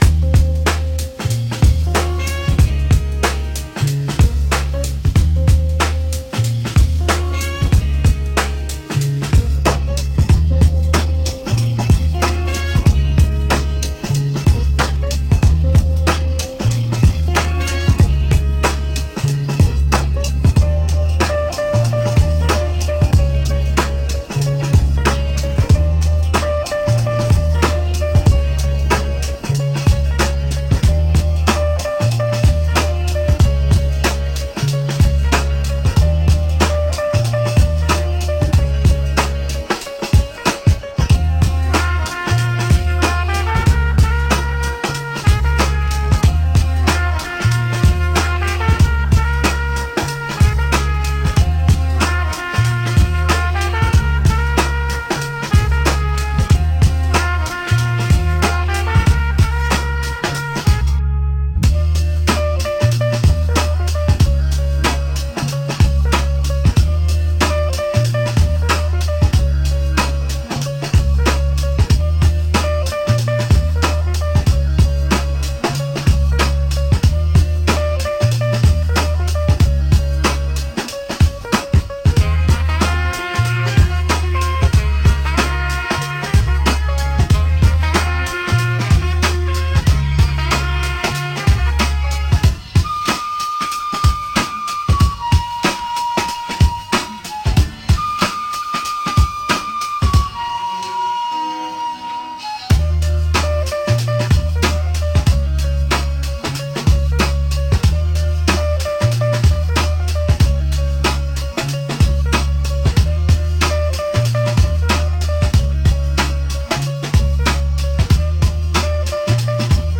128 BPM
Trap